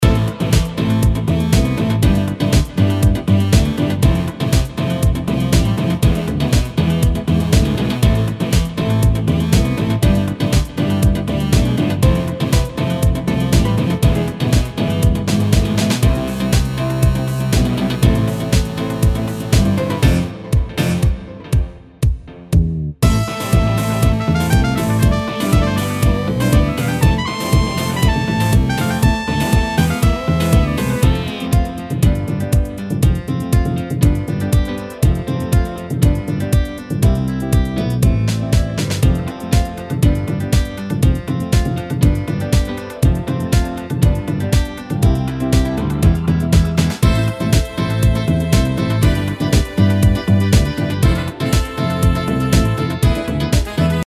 Mixage professionnel
Audio de qualité studio